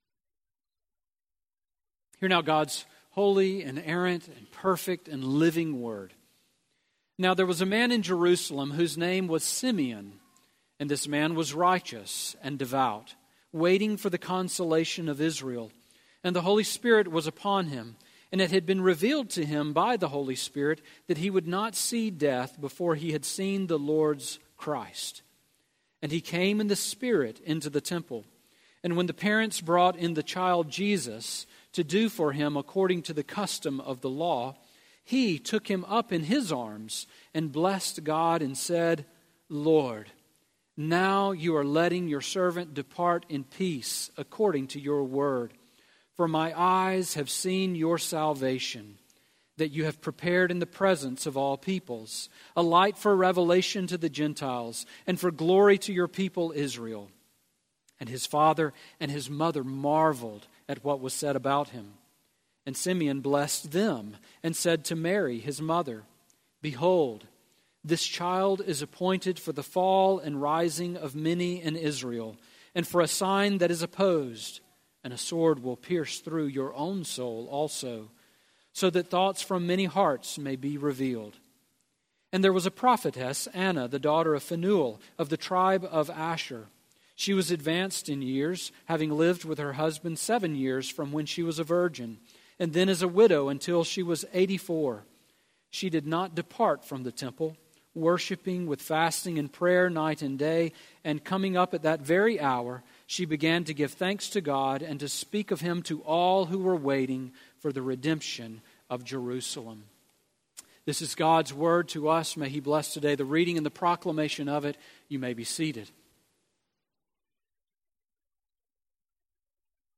Sermon on Luke 2:25-38 from January 1